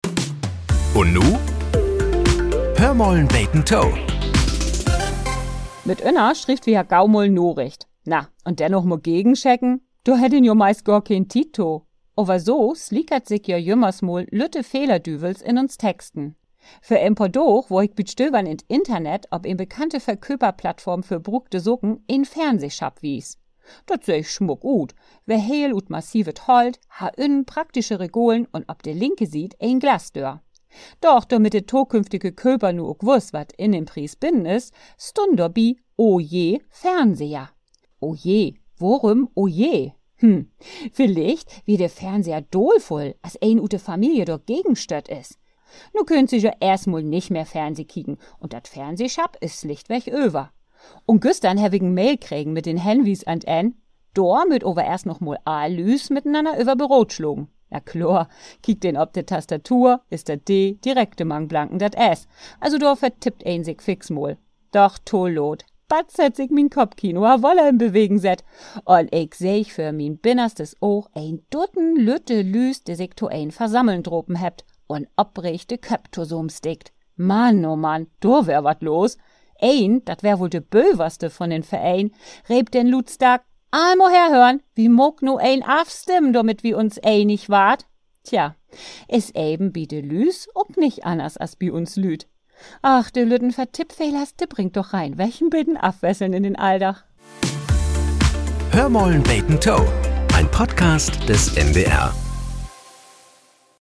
Nachrichten - 25.02.2025